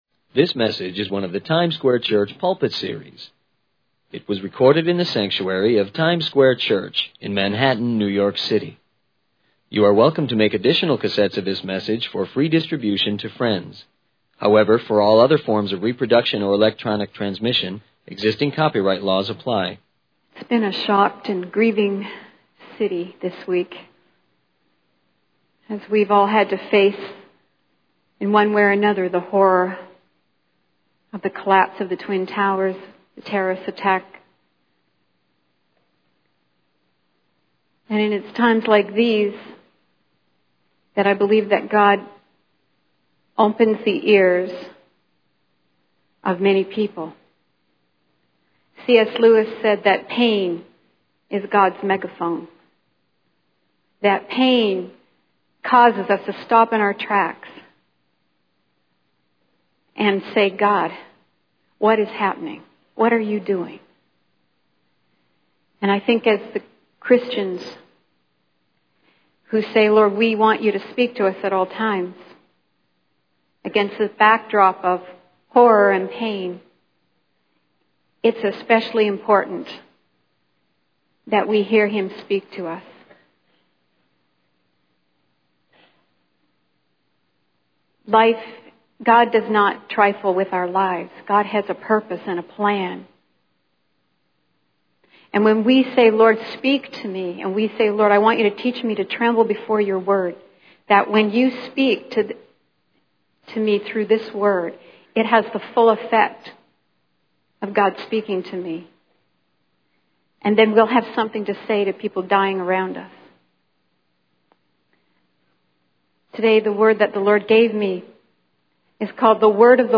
It was recorded in the sanctuary of Times Square Church in Manhattan, New York City.